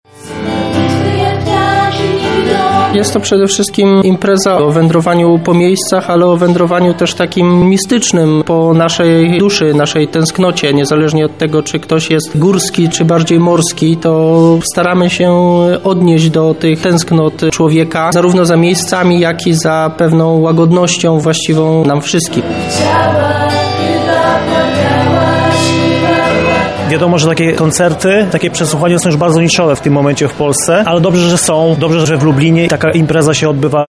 Choć za oknem aura niezbyt zimowa, w miniony weekend w ramach Lubelskiego Przeglądu Poetyckiego „Strojne w Biel” spotkali się miłośnicy poezji, tej śpiewanej i recytowanej.